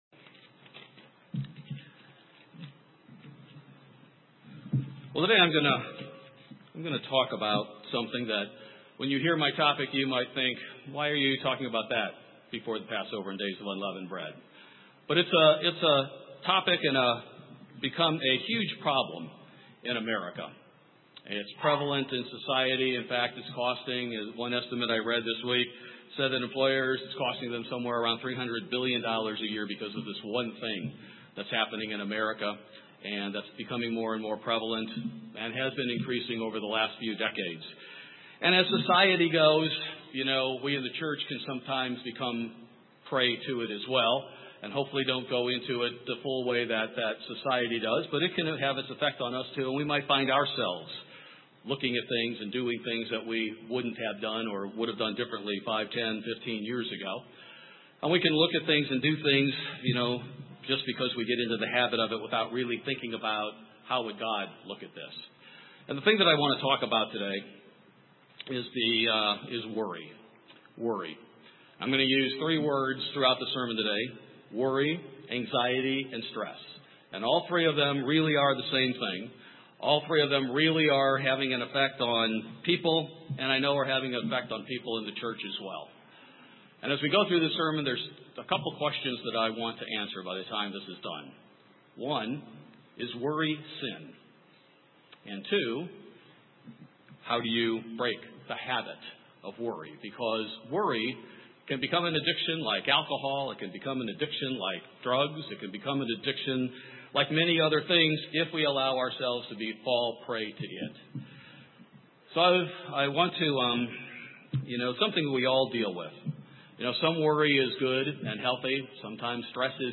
Doctors try to treat it with a variety of medications, but the Bible gives the cure. In this sermon, we will answer two questions: 1) is worry a sin; and 2) what is the Biblical solution to this universal problem?